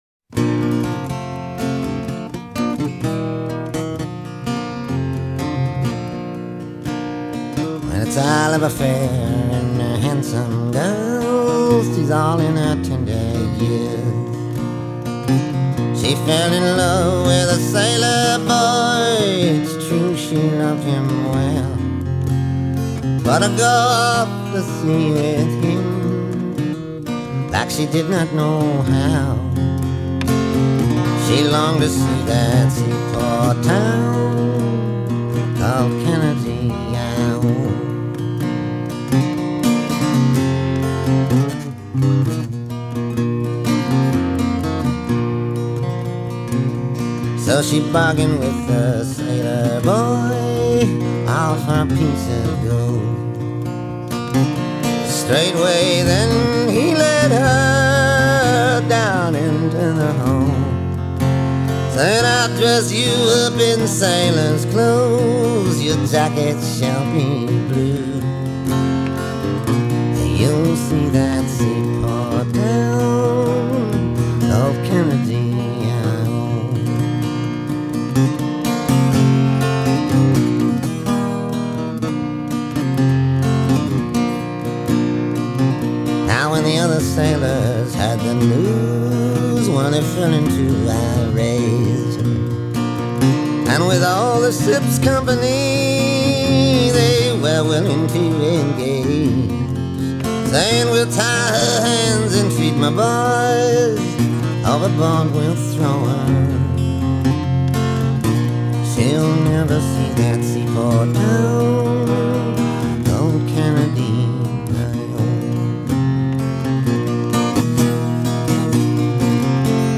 90s folk song covers